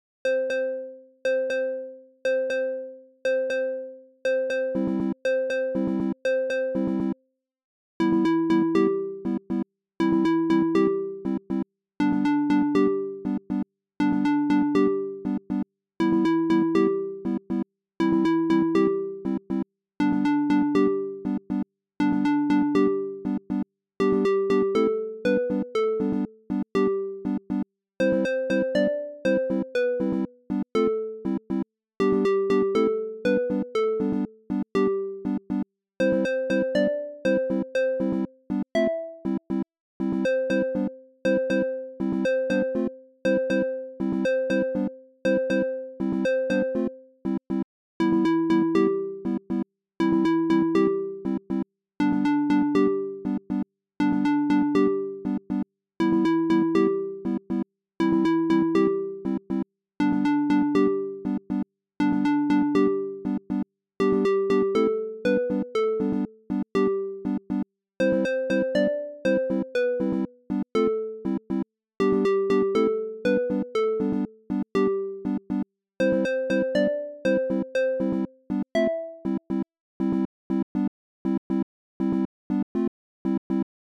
ファミコンの横スクロールアクションの最初のステージ風BGM！
ループ：◎
BPM：120 キー：C ジャンル：あかるい 楽器：ファミコン